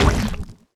etfx_explosion_slime2.wav